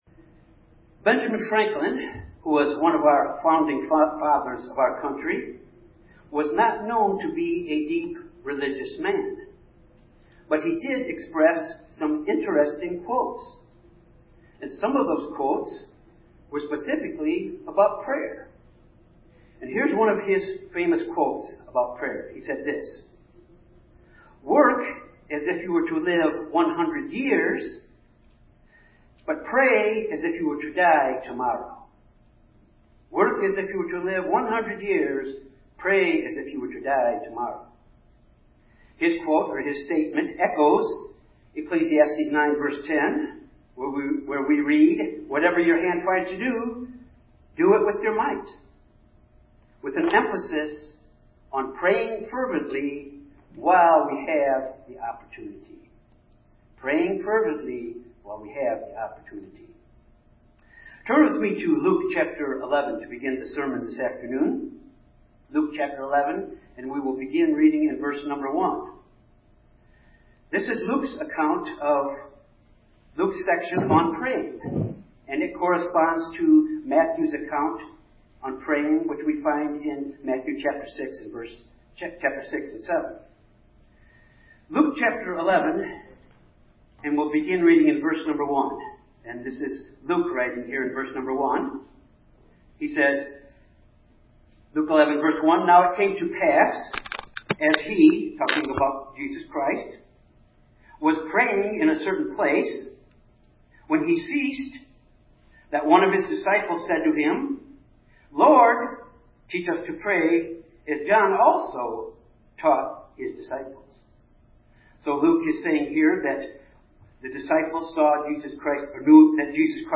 This sermon examines the importance of being persistence in prayer in our spiritual lives.
Given in Jonesboro, AR Little Rock, AR